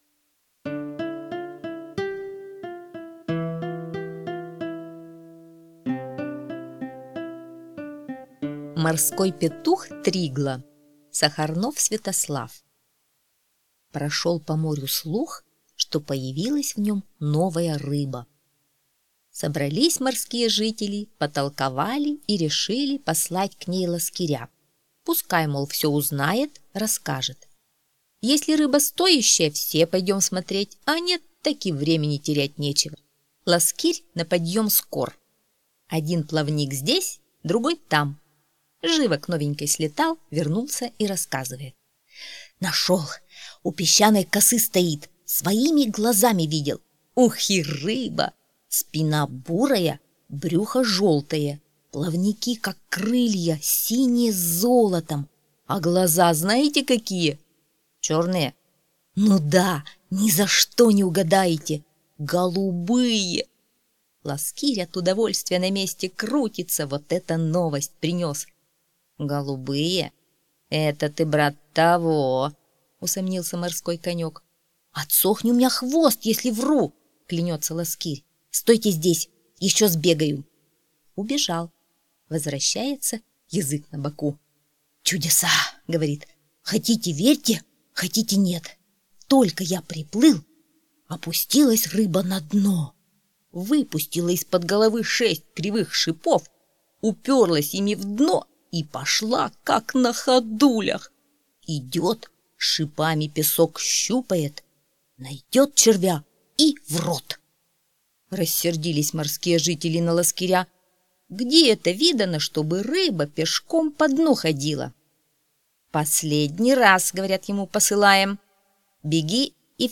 Морской петух-тригла — аудиосказка Сахарнова С.В. Слушайте сказку «Морской петух-тригла» онлайн на сайте Мишкины книжки.